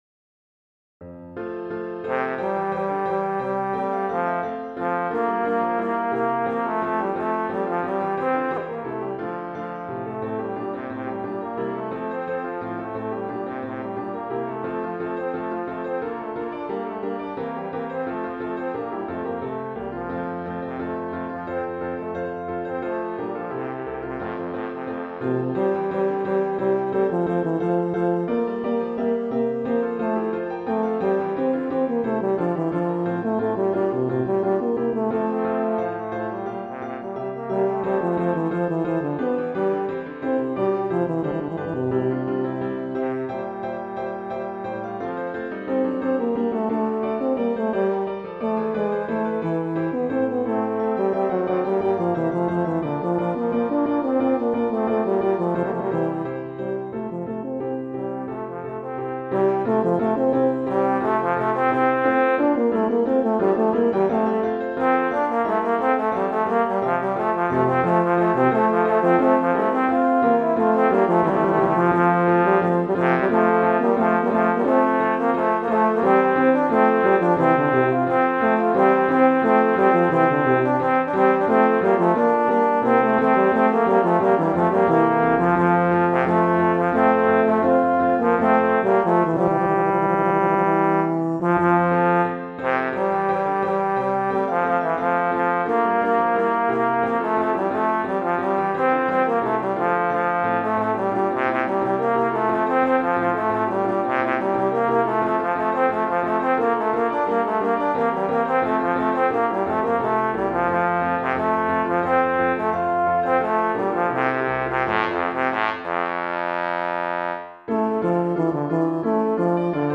Voicing: Trombone/Tuba Duet